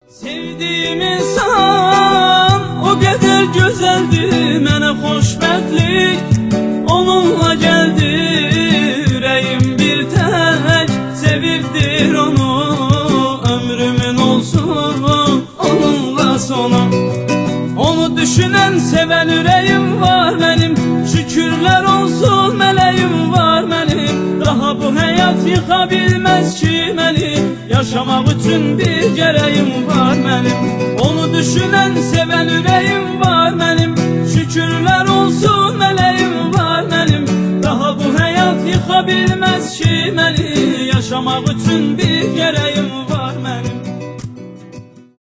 инструментальные
душевные